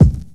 Boom-Bap Kick 59.wav